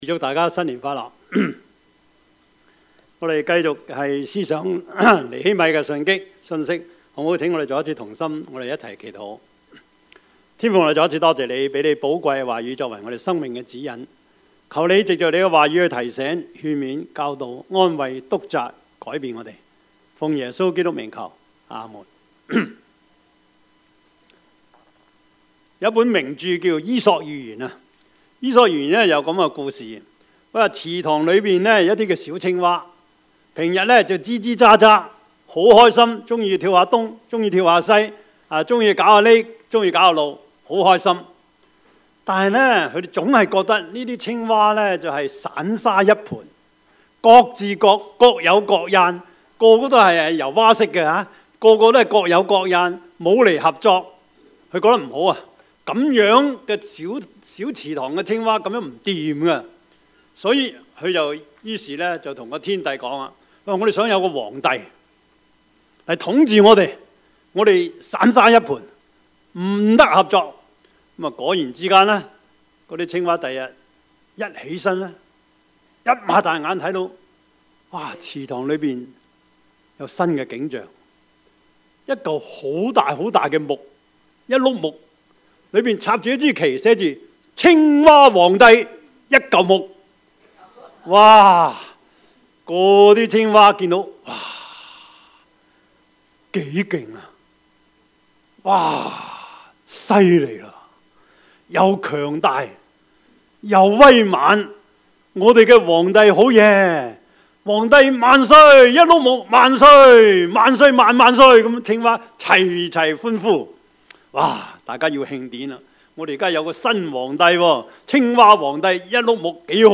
Service Type: 主日崇拜
Topics: 主日證道 « 讀經有什麼味道?